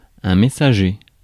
Prononciation
Prononciation France: IPA: [mɛ.sa.ʒe] Le mot recherché trouvé avec ces langues de source: français Traduction 1.